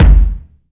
kick 23.wav